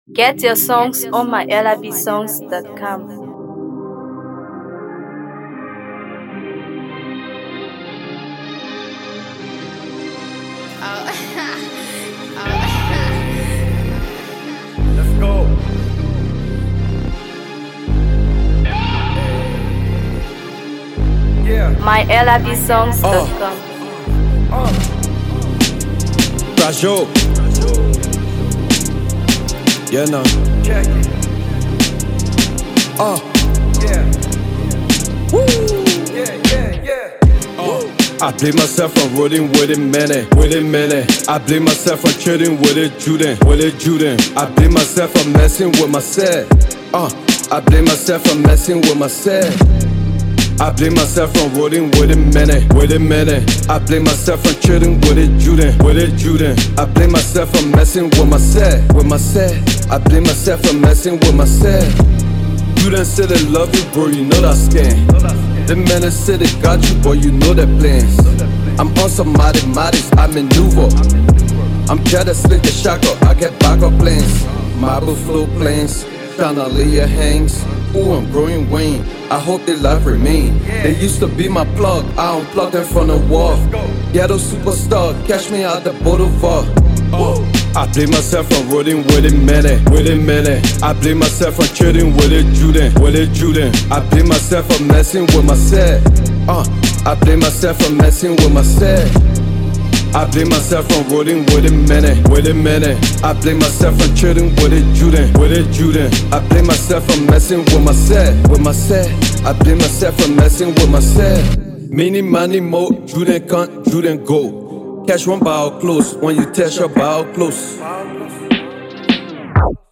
Hip HopMusic